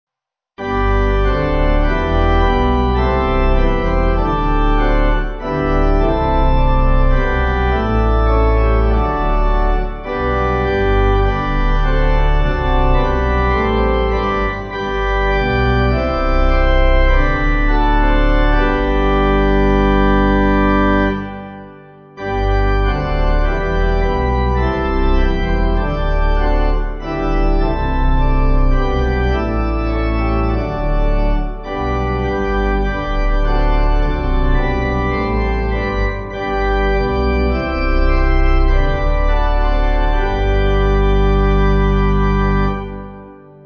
Organ
(CM)   4/G